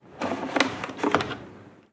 Abrir la puerta de una lavadora 1
Sonidos: Acciones humanas
Sonidos: Hogar